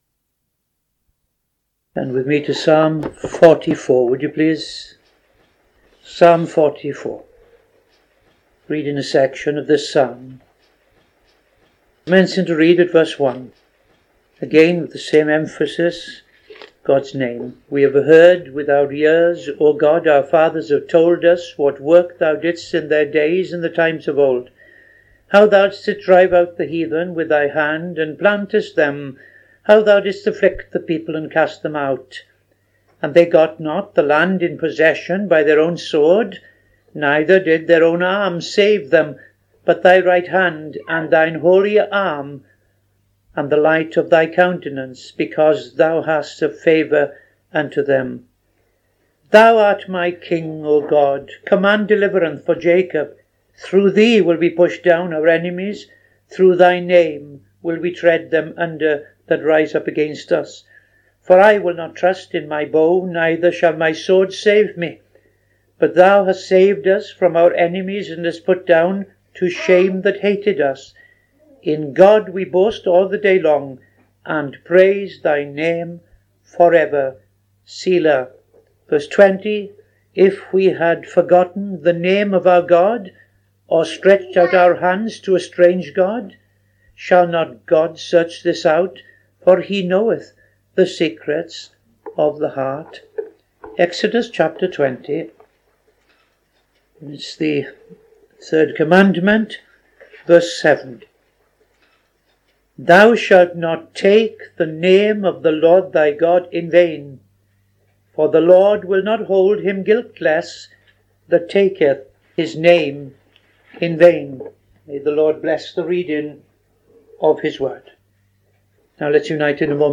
Reading – Psalm 44:1-5, 20-21; Exodus 20:7